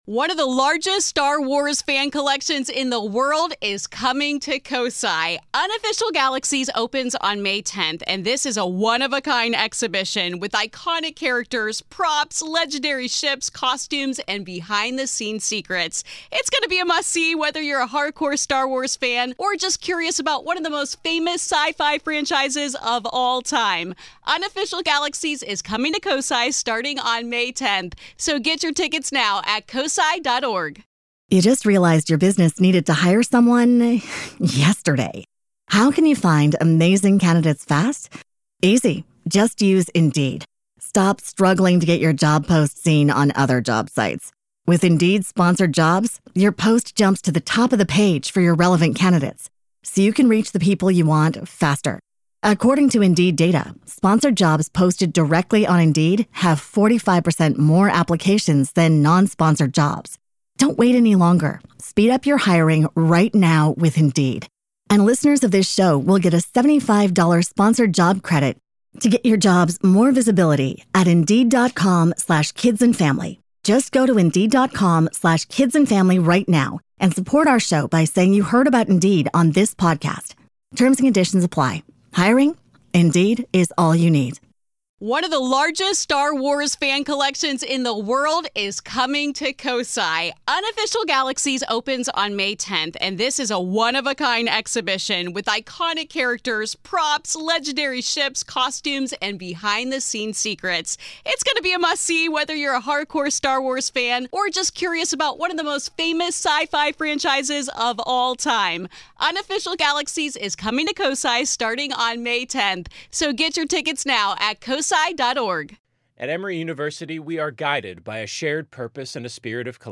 In this emotional and chilling story, a mother recounts her son’s lifelong connection to the paranormal, starting from the moment of his difficult delivery, when a mysterious man sat silently in the h...
Grave Confessions is an extra daily dose of true paranormal ghost stories told by the people who survived them!